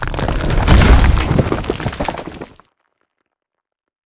combat / megasuit / step2.wav